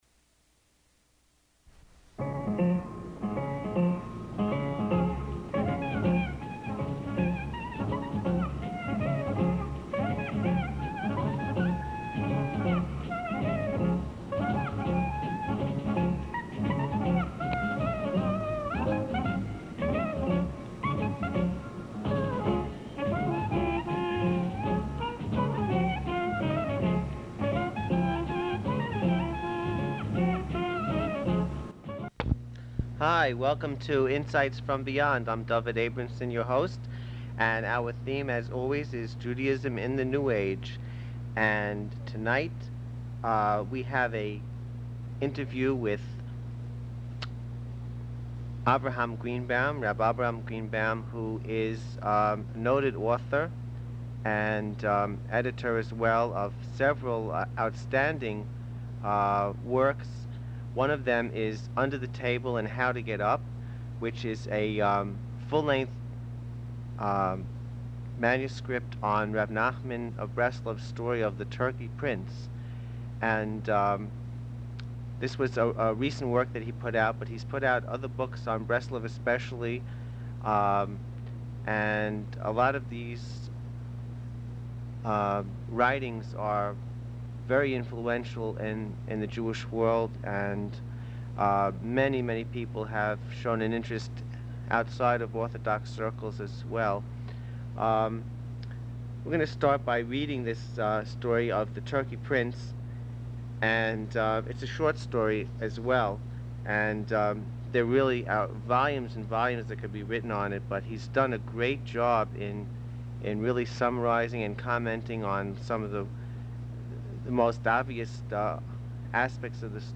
..from the radio broadcast Insights from Beyond.